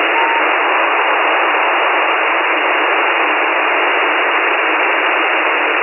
GoesDCS1200bps.mp3